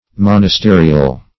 Search Result for " monasterial" : The Collaborative International Dictionary of English v.0.48: Monasterial \Mon`as*te"ri*al\, a. [L. monasterials, fr. monasterium.] Of or pertaining to monastery, or to monastic life.